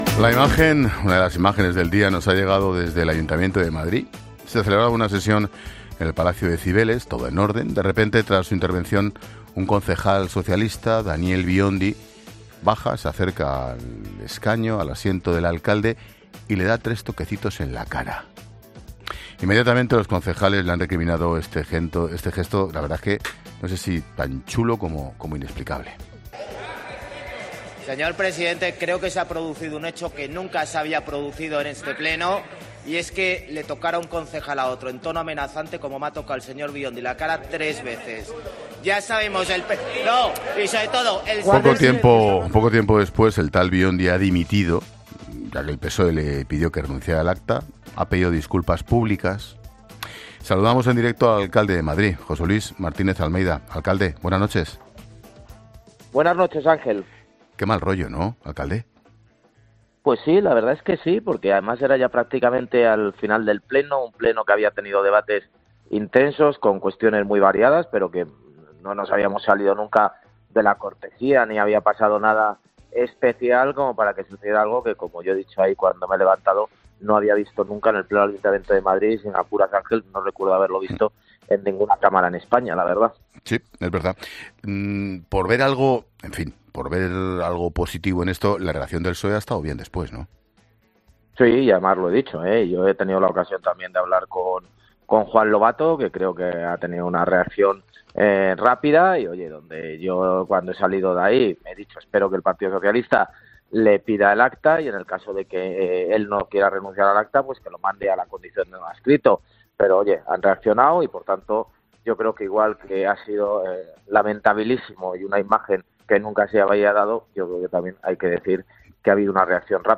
Almeida ha explicado en La Linterna que todo ha sido un episodio desagradable e inesperado: "Era casi al final del Pleno. Un Pleno que había tenido debates con cuestiones muy variadas, pero no nos habíamos salido nunca de la cortesía para que pasara nada". Añade que "nunca había visto nada parecido en ninguna Cámara en España".